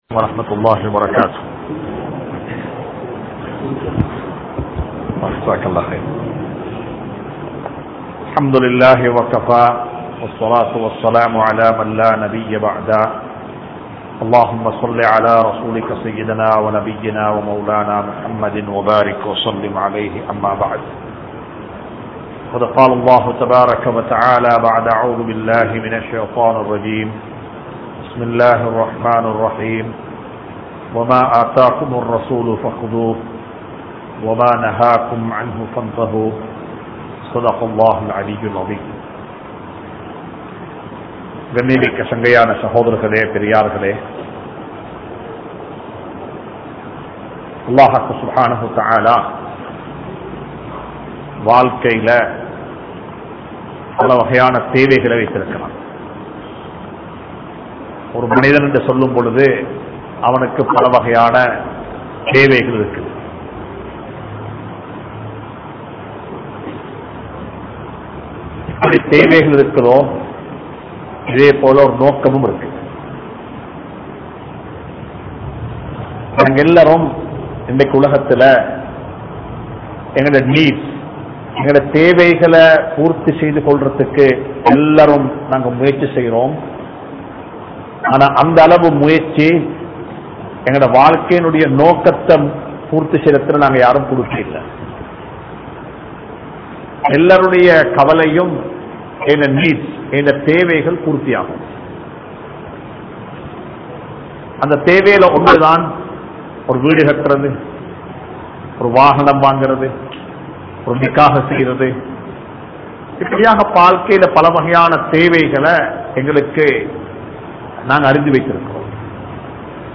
Elimaiyaana Thirumanam (எளிமையான திருமனம்) | Audio Bayans | All Ceylon Muslim Youth Community | Addalaichenai
Ibrahimiya Masjidh